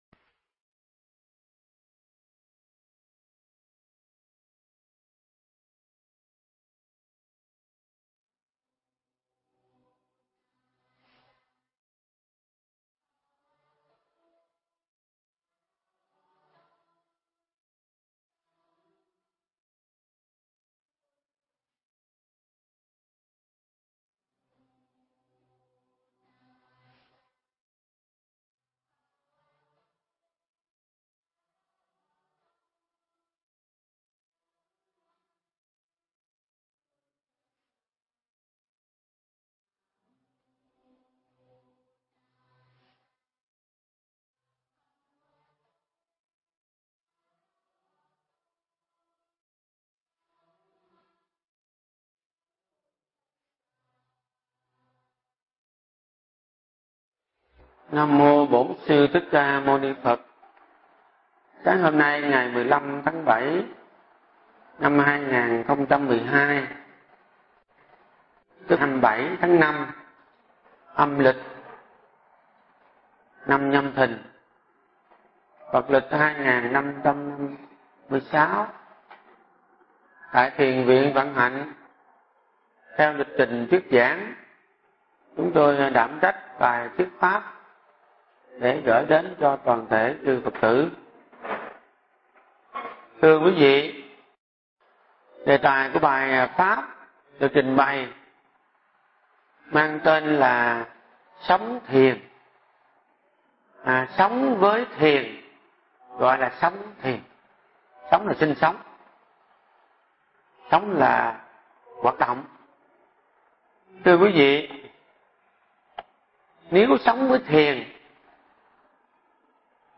Nghe Mp3 thuyết pháp Sống Thiền